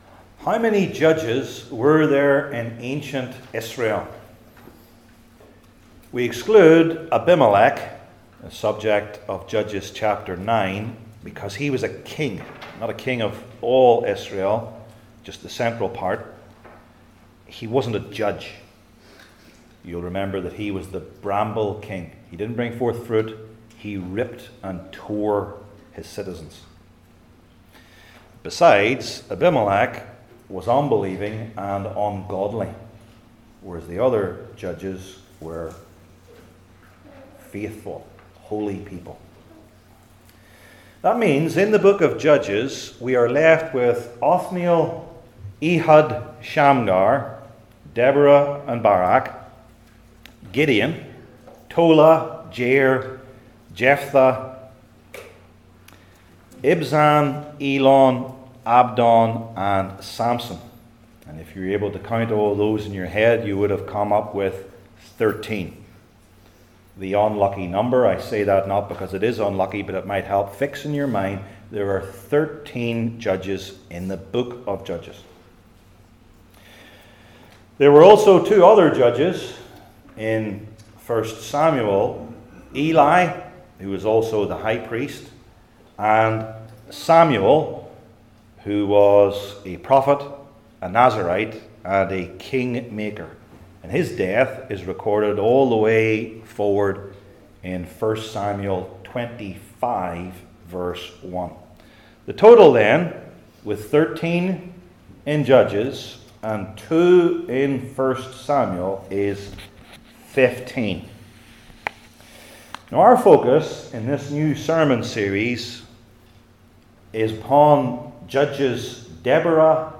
Old Testament Sermon Series I. The Just Sale II. The Weak Deliverers